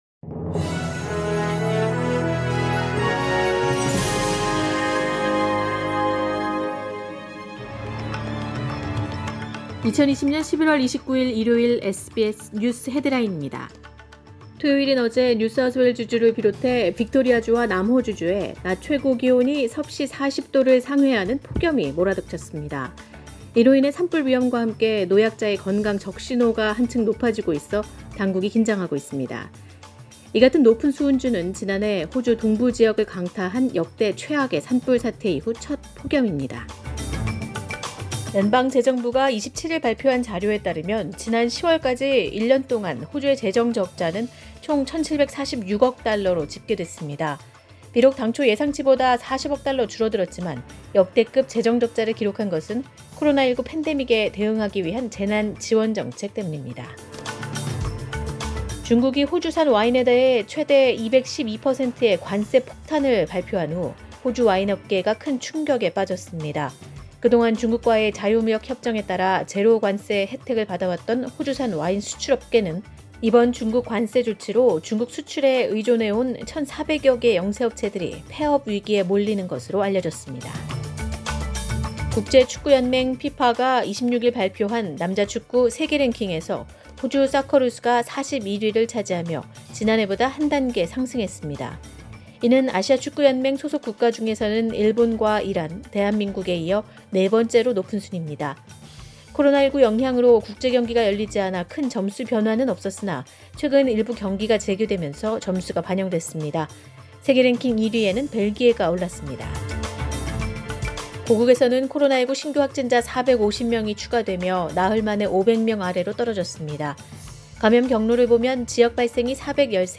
SBS News Headlines…2020년 11월 29일 오전 주요 뉴스
2020년 11월 29일 일요일 오전의 SBS 뉴스 헤드라인입니다.